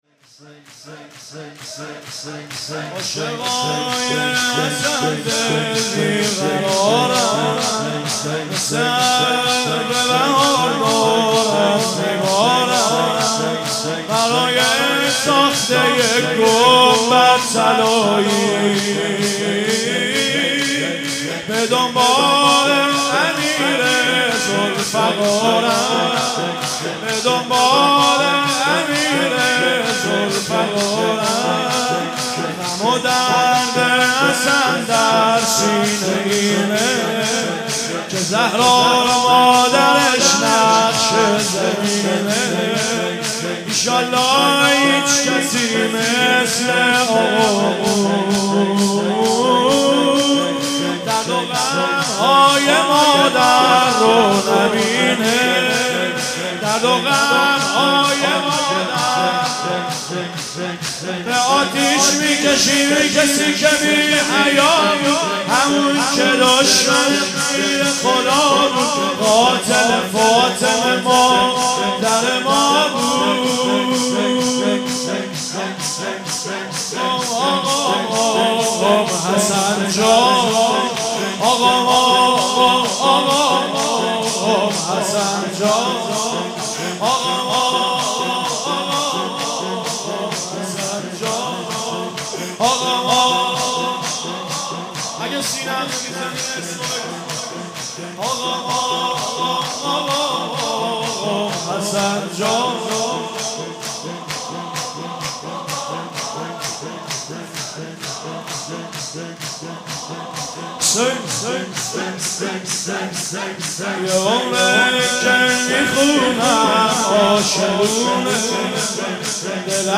مراسم عزاداری و سوگواری
مداحی
دانشگاه امیرکبیر تهران